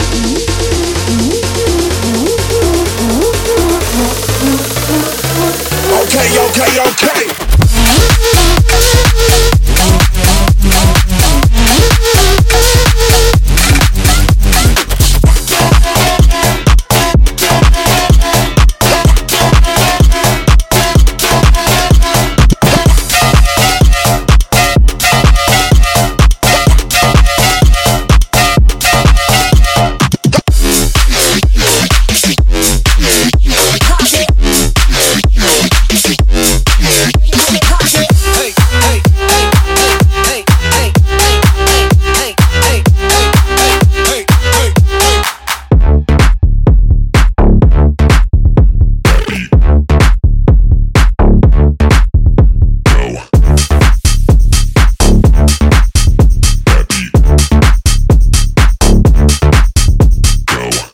您会发现各种令人印象深刻的硬击式贝司，大量的主音和令人惊叹的弹拨声-所有这些都可以轻松自定义